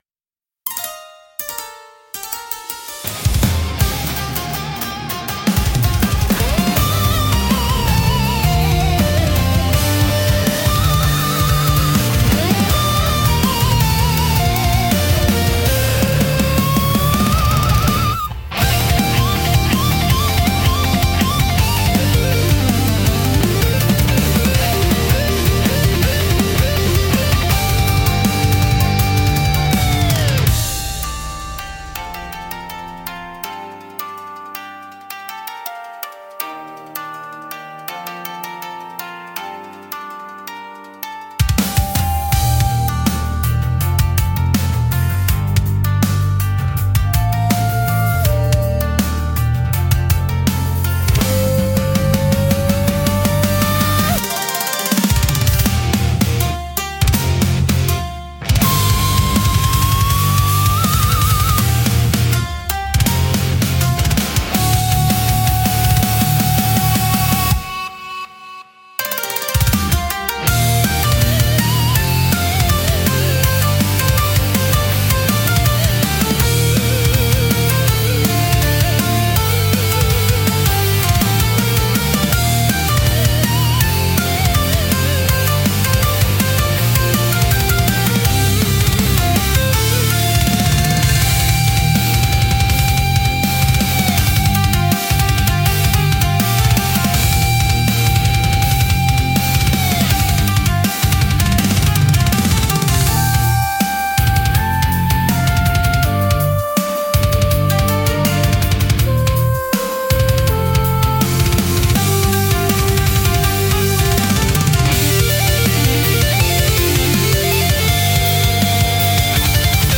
尺八の幽玄な響きと琴の繊細な調べが、重厚なギタートーンや高速ビートと絡み合い、独自の緊張感とダイナミズムを生み出します。